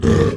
spawners_mobs_uruk_hai_hit.5.ogg